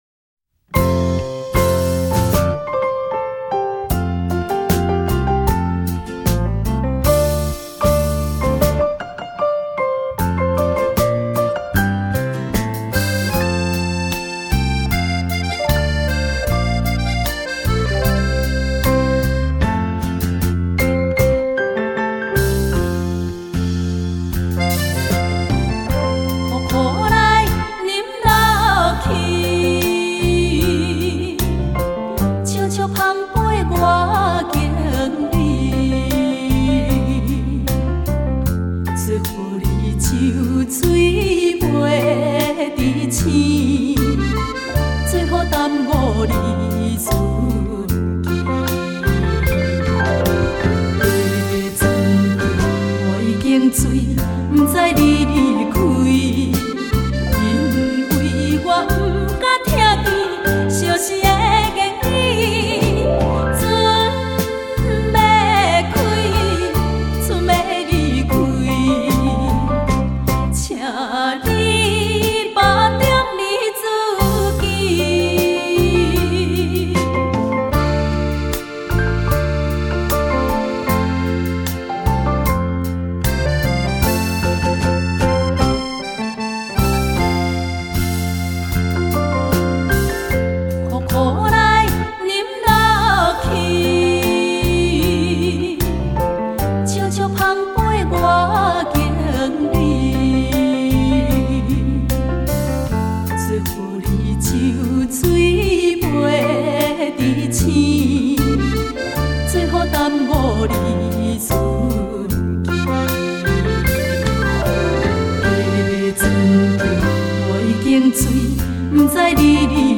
优美的歌声琴声牵动您的心